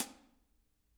R_B Hi-Hat 03 - Close.wav